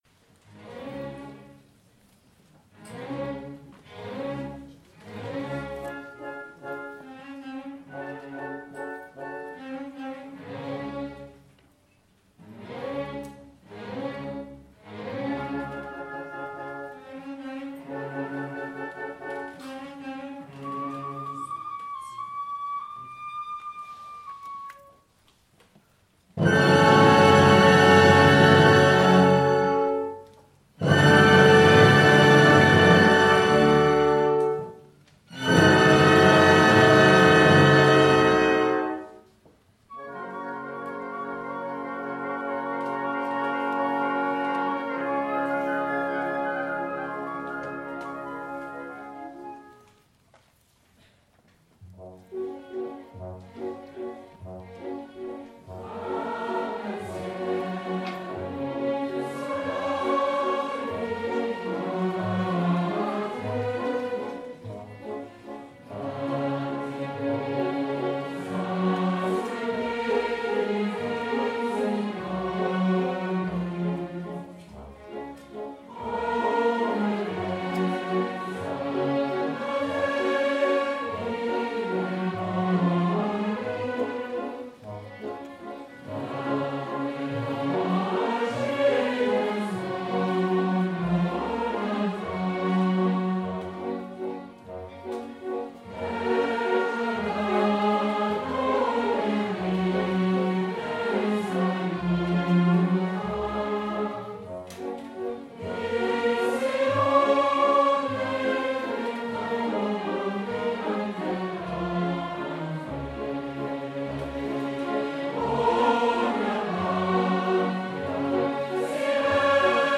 A l’occasion de ces concerts gratuits (6e édition) pour offrir la culture lyrique à tous, les quelque 1500 spectateurs ravis ont pu entendre près de 220 intervenants sur la scène du Tram et de l’Arsenal au cours de deux représentations sur le thème du voyage musical comme moteur de fraternité entre les peuples et les nations, une nationalité universelle et sans frontière.
Les trois chœurs chantent le « Va pensiero » de l’opéra Nabucco de Verdi :